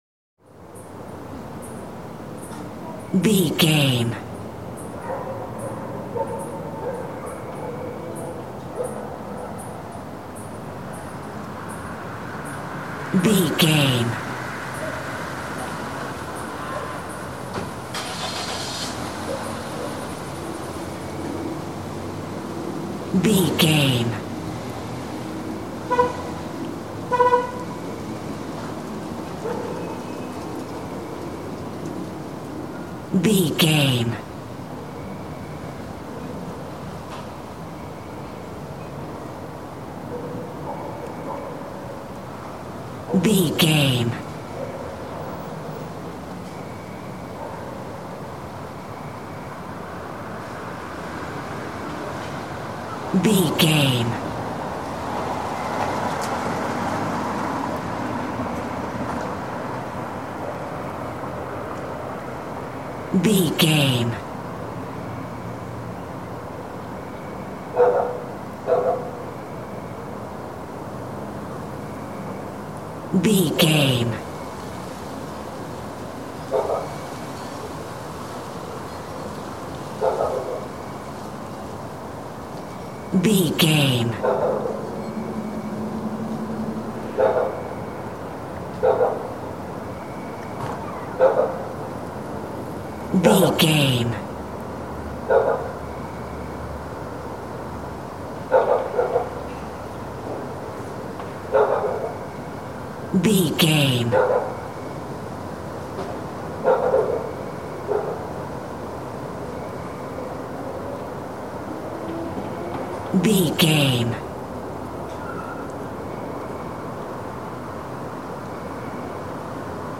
City rumble wind night
Sound Effects
chaotic
tension
urban
ambience